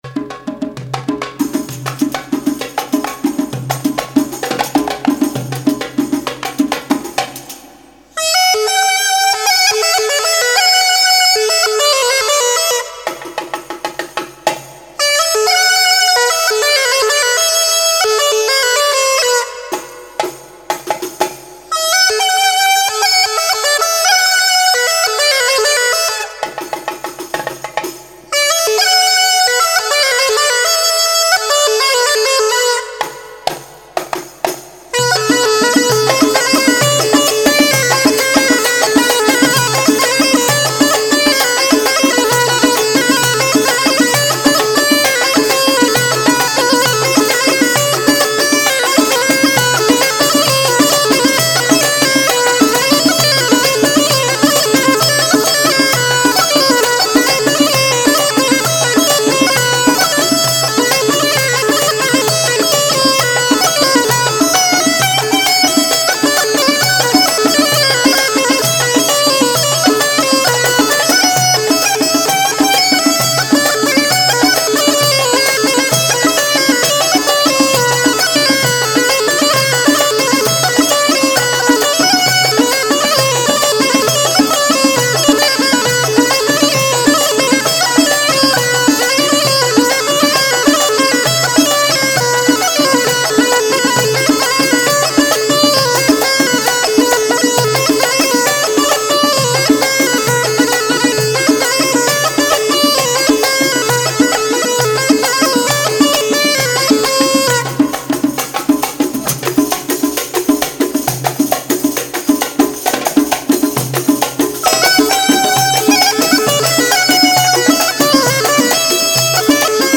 Bikalaam